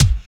50 KICK 3.wav